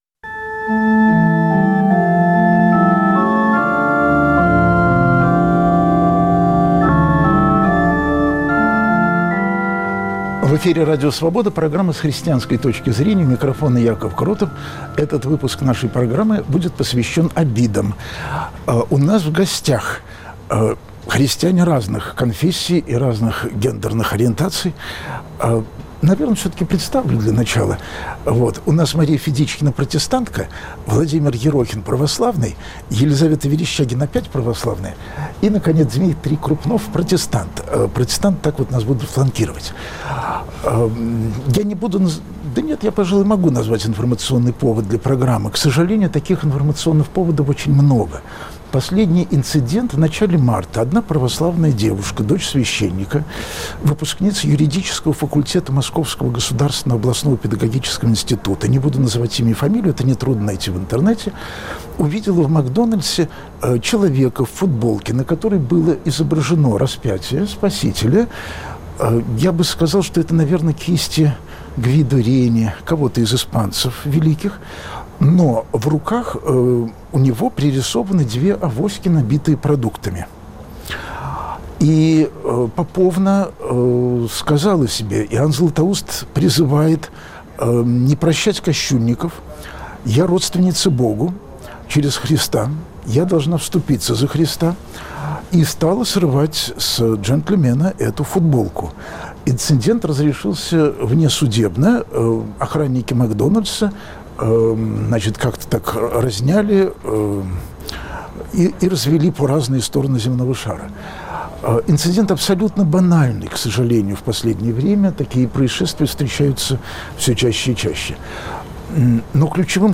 В программе, которую ведет священник Яков Кротов, слушают друг друга верующие и неверующие, чтобы христиане в России были не только большинством, но и работниками свободы Божьей и человеческой.